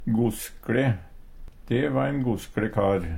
goskLe - Numedalsmål (en-US)
Høyr på uttala Ordklasse: Adjektiv Kategori: Karakteristikk Attende til søk